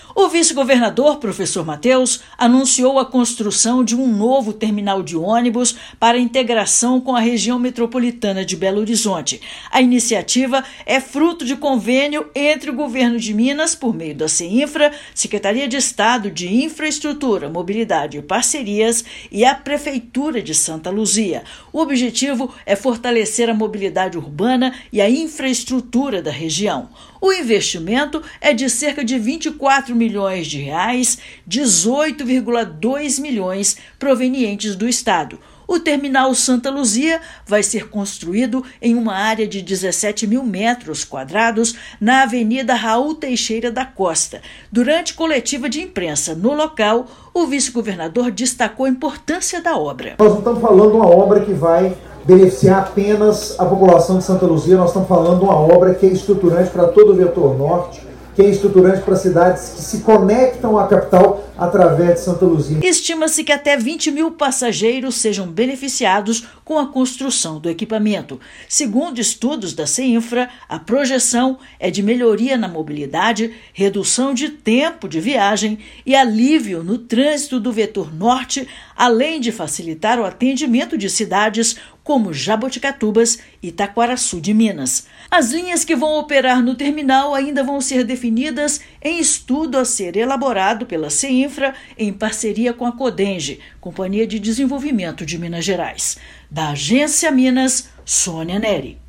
[RÁDIO] Governo de Minas anuncia construção de terminal de ônibus metropolitano em Santa Luzia